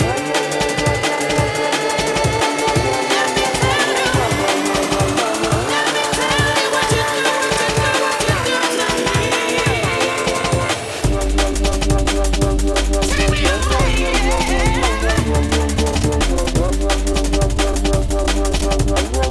Дайте дорогу к такому бэйслайну пожалуйста